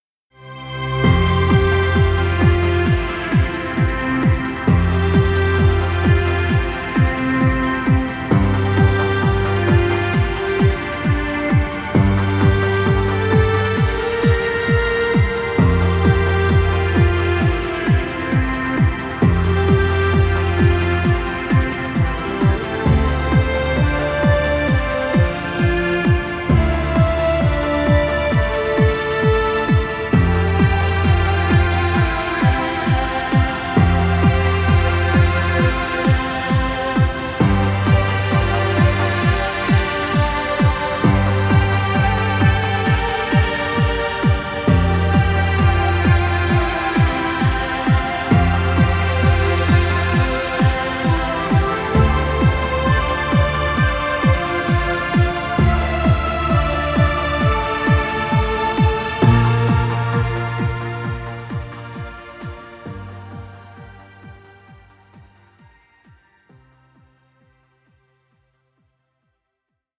Dance music.